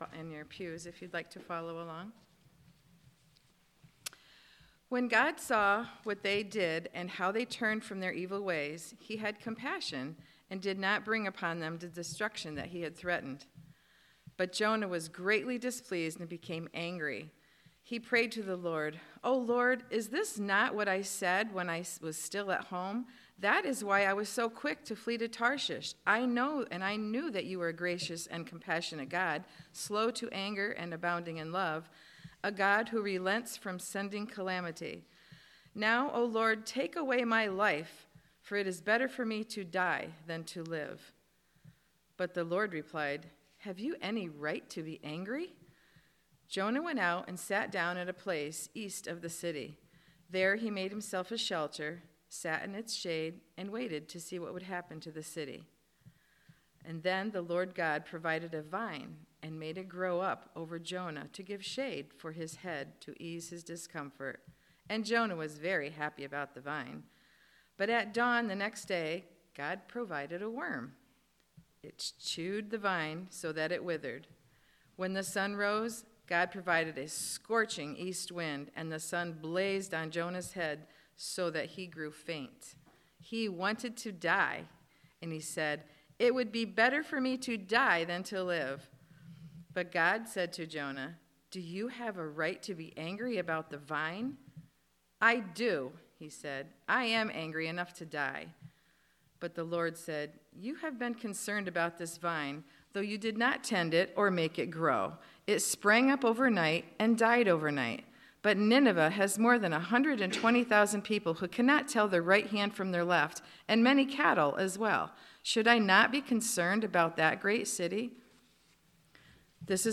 Worship Service – October 16, 2022 « Franklin Hill Presbyterian Church